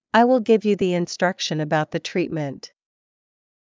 ｱｲ ｳｨﾙ ｷﾞｳﾞ ﾕｰ ｼﾞ ｲﾝｽﾄﾗｸｼｮﾝ ｱﾊﾞｳﾄ ｻﾞ ﾄﾘｰﾄﾒﾝﾄ